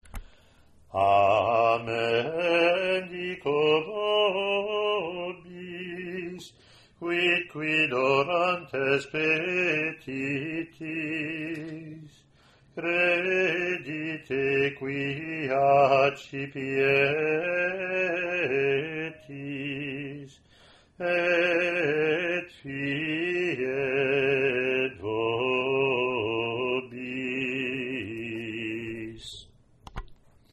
Year BC Latin antiphon + verses)